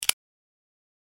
دانلود آهنگ کلیک 50 از افکت صوتی اشیاء
جلوه های صوتی